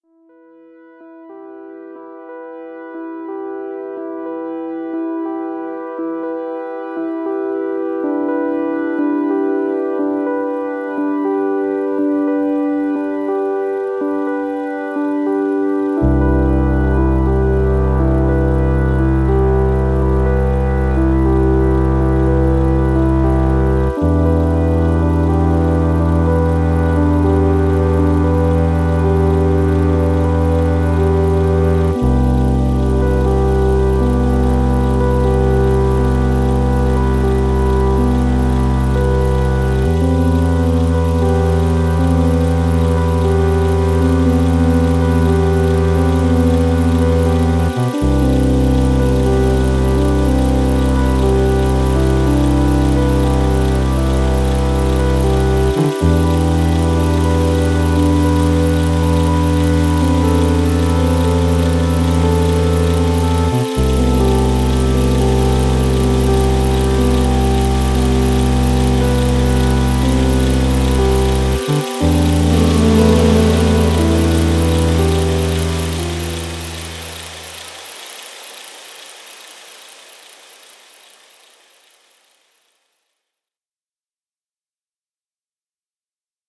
electronica enveloppante
et electro pop plus enjouée